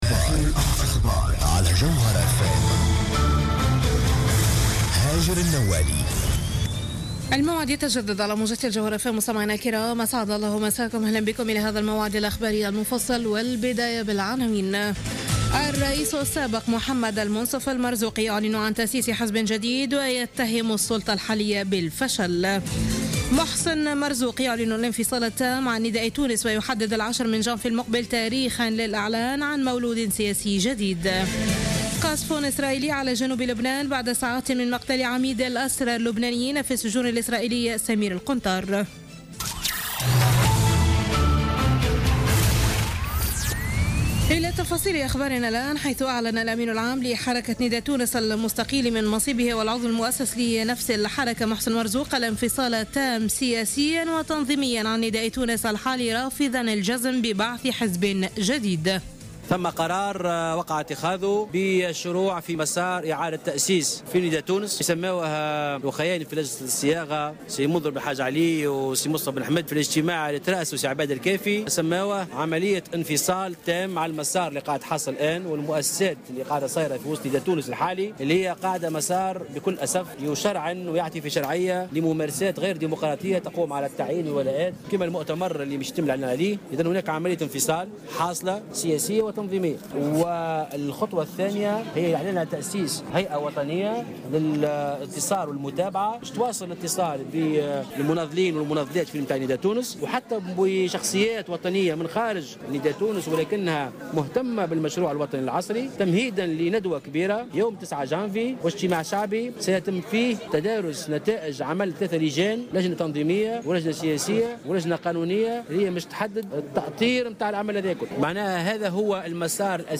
نشرة أخبار منتصف الليل ليوم الإثنين 21 ديسمبر 2015